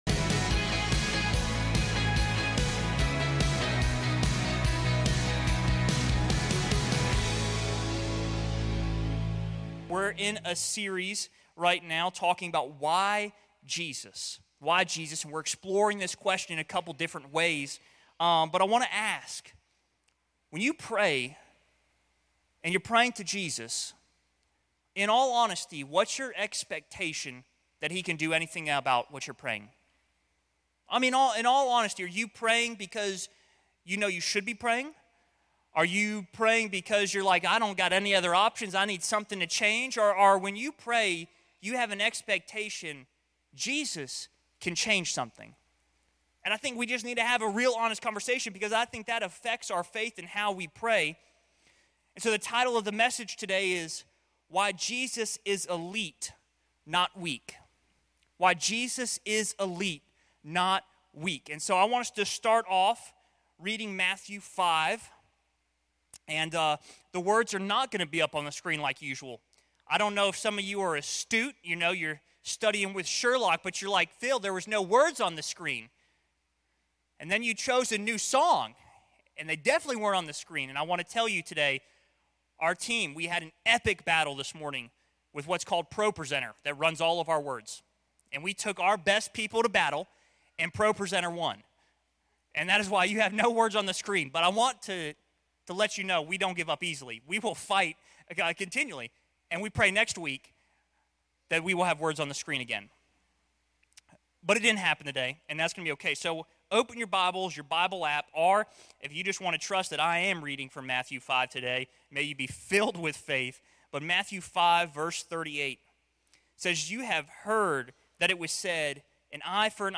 Join us for another sermon in our series "Why Jesus." Because of how meek Jesus is, sometimes people interpret Jesus as weak.